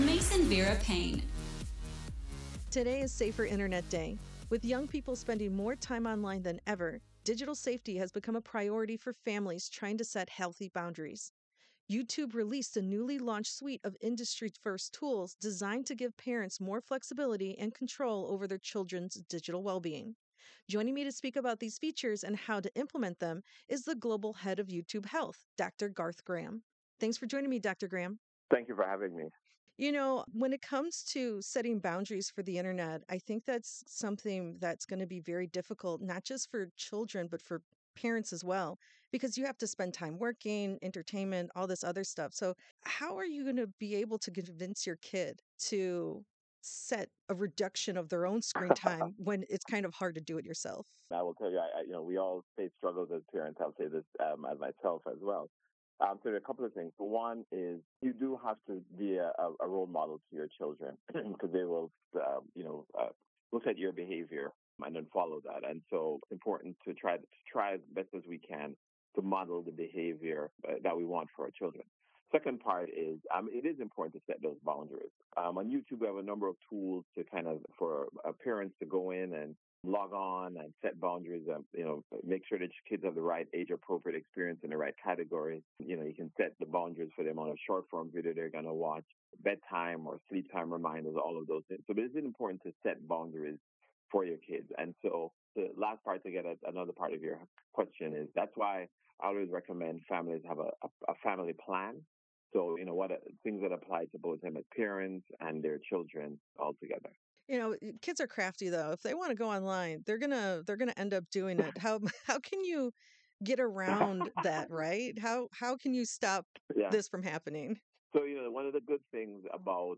Safer Internet Day Transcript